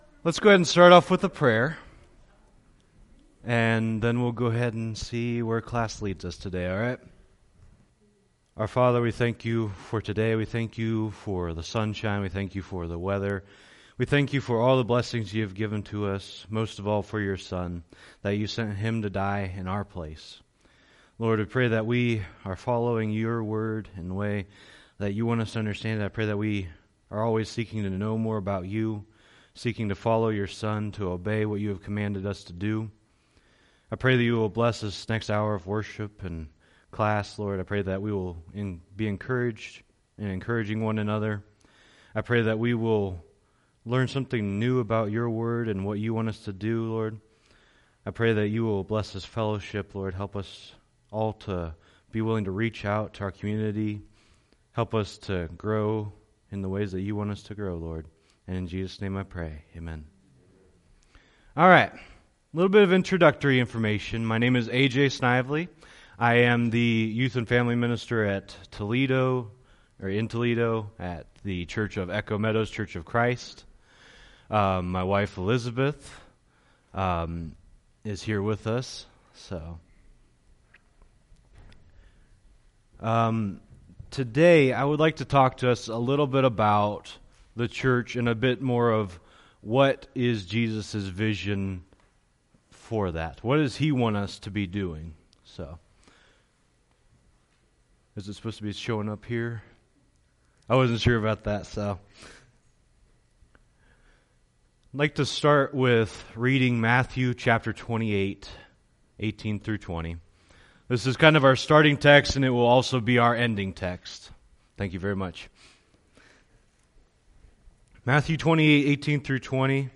Wells & Fences (Bible Study)